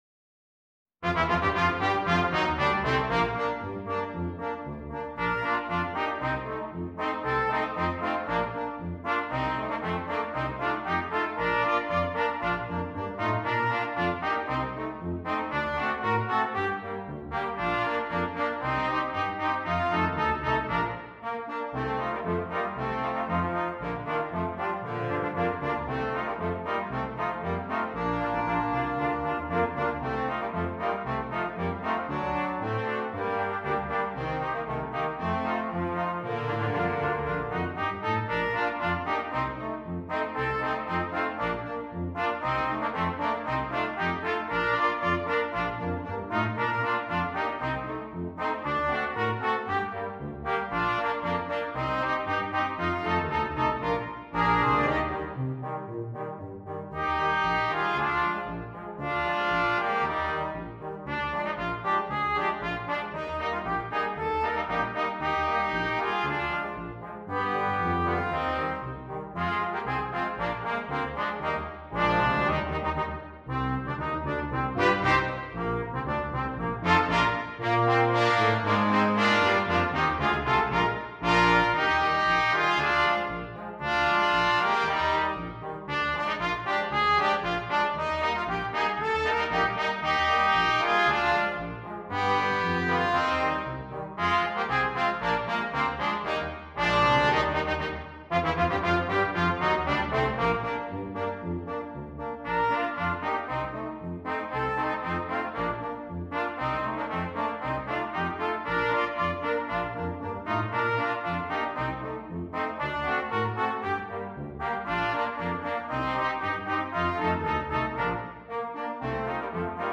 Brass Quintet
a tribute to the German-Czech style polkas